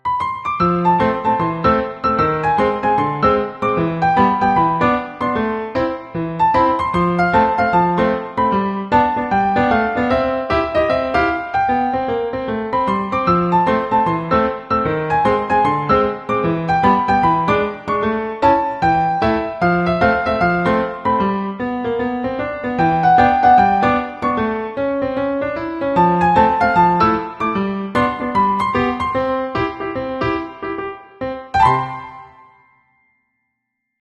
Light sussex, barred plymouth rock